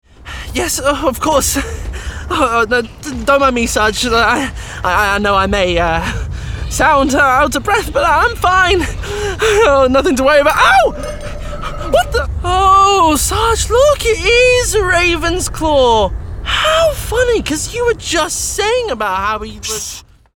Voice Reel
Animation - Young, Expressive